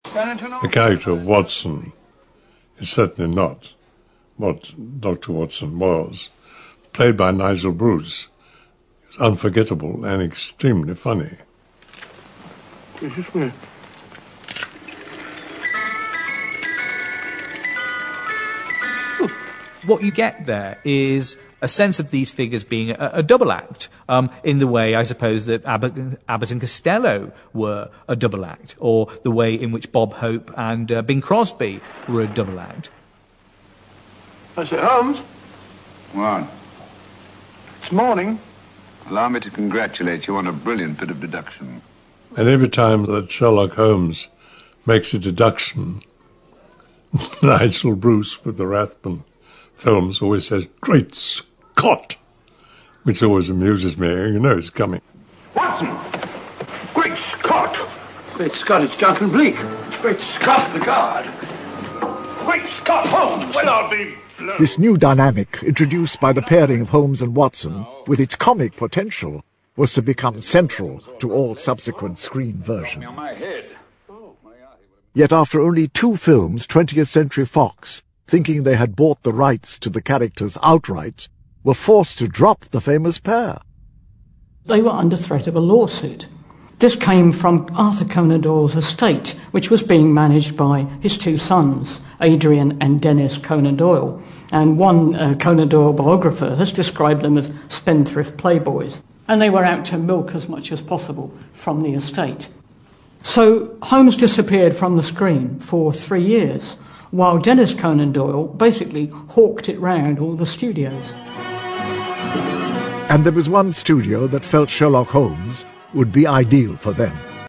在线英语听力室如何成为福尔摩斯 第10期的听力文件下载, 《如何成为福尔摩斯》栏目收录了福尔摩斯的方法，通过地道纯正的英语发音，英语学习爱好者可以提高英语水平。